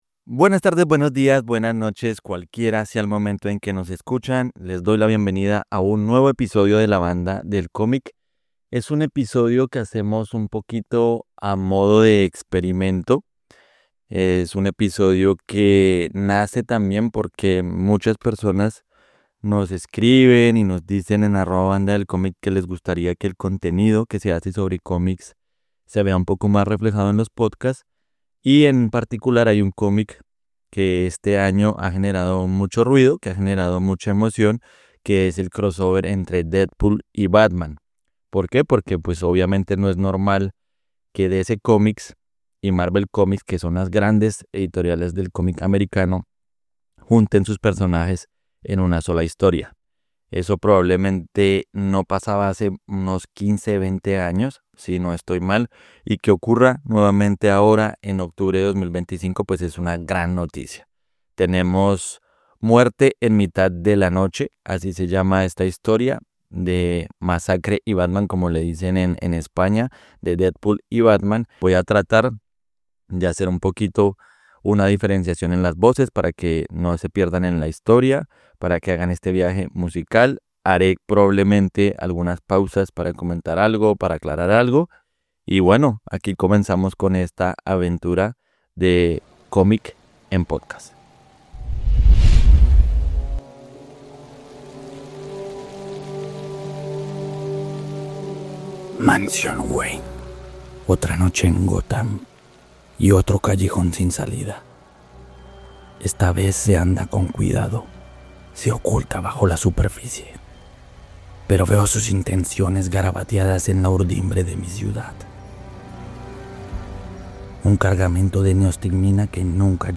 Batman y Deadpool: lectura de cómic